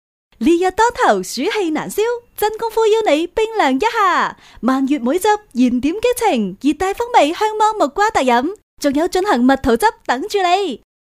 9 女粤23_广告_食品饮料_真功夫_活力 女粤23
女粤23_广告_食品饮料_真功夫_活力.mp3